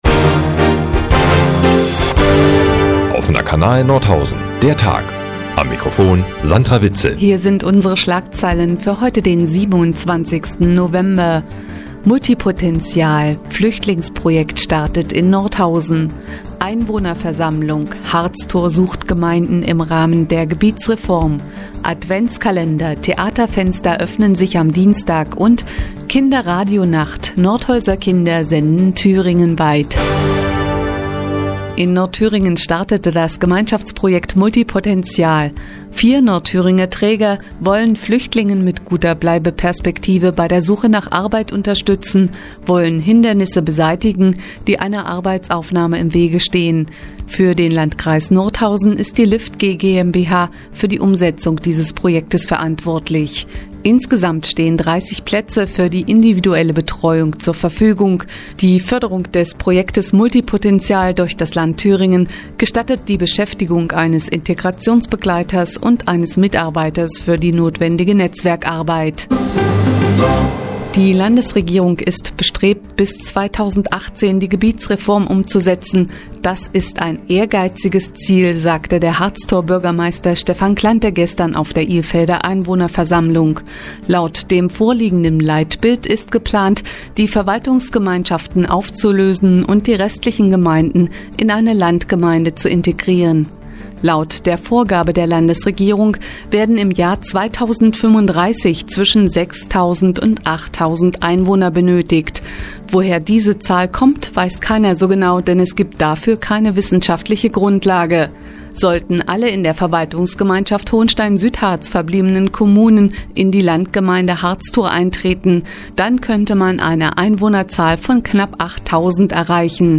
Die tägliche Nachrichtensendung des OKN ist jetzt hier zu hören...